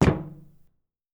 Added head bob & footstep SFX
metal4.wav